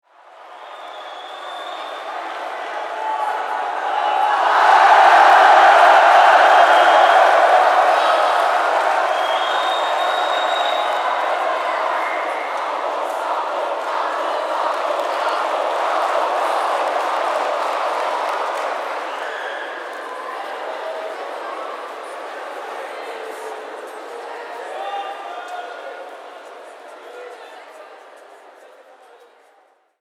Download Stadium Crowd sound effect for free.
Stadium Crowd